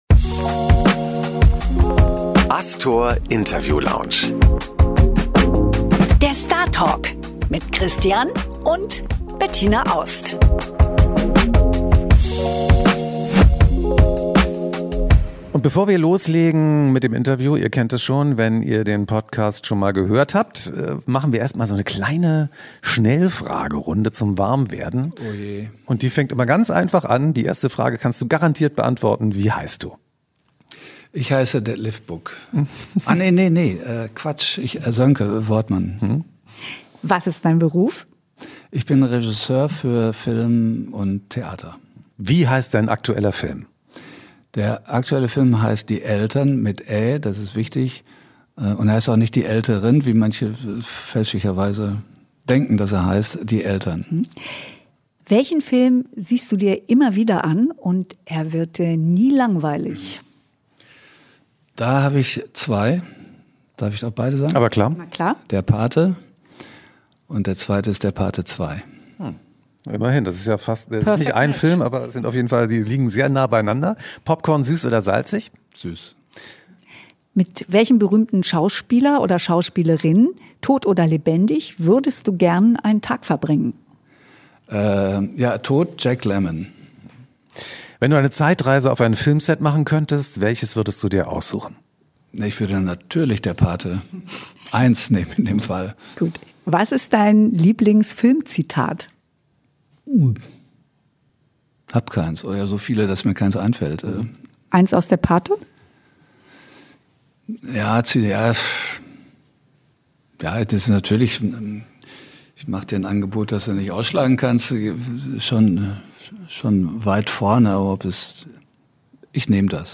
Im Podcast Astor Interview Lounge ist diesmal den Regisseur Sönke Wortmann zu Gast!